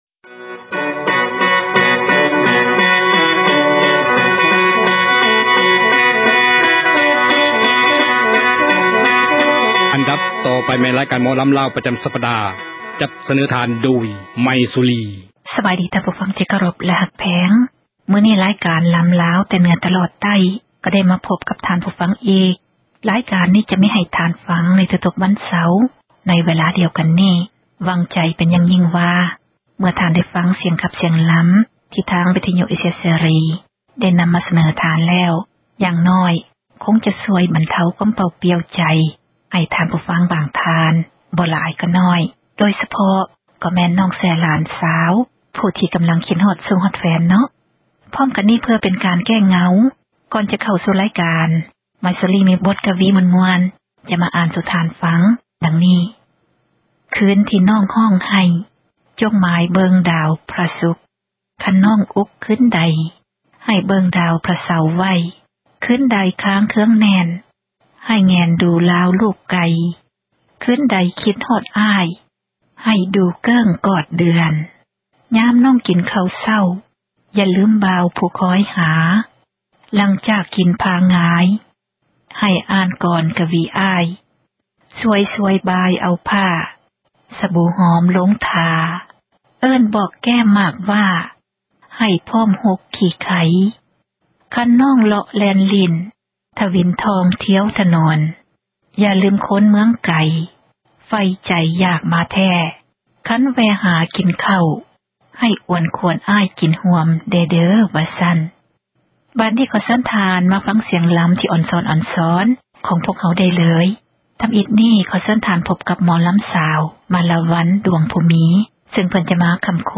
ອັນດັບຕໍ່ໄປ ແມ່ນຣາຍການ ໝໍລໍາລາວ ປະຈໍາສັປດາ.